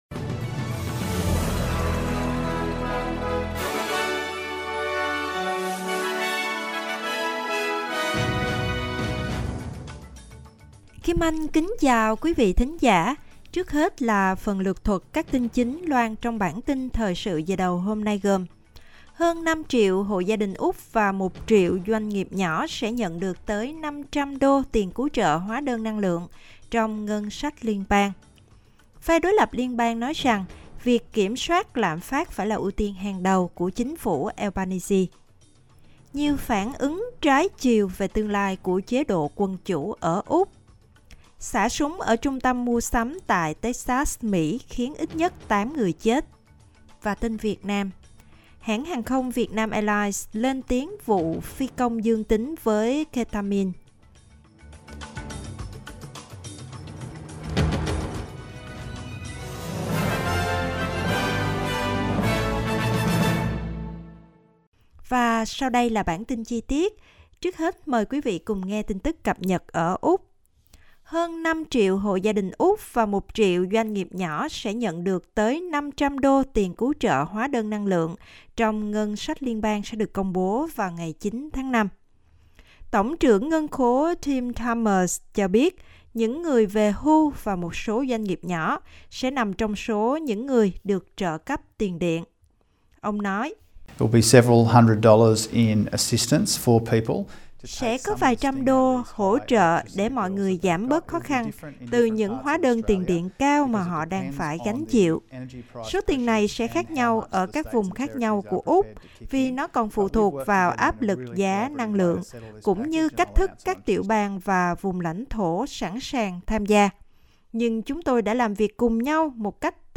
Bản tin chủ Nhật 7-5-2023